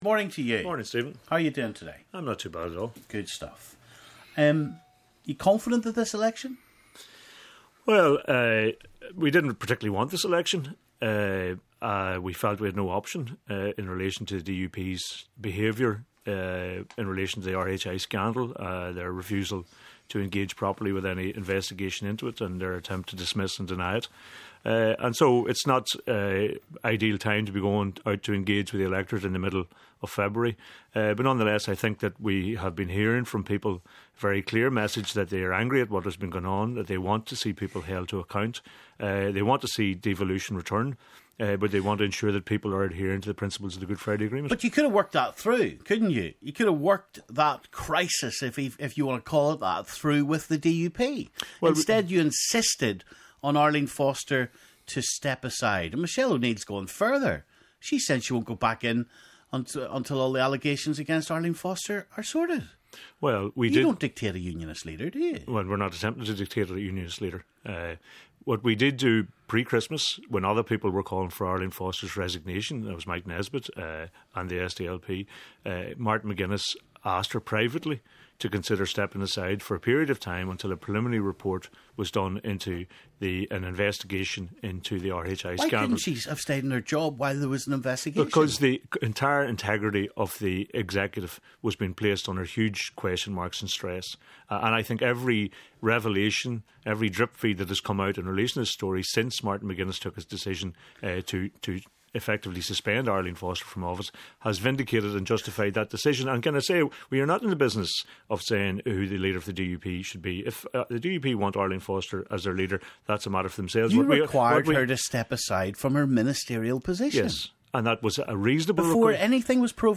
Time for another of our election special manifesto crunching interviews and one of the big parties today, Sinn Fein. Can they deliver on what they are promising? Conor Murphy speaks to Stephen.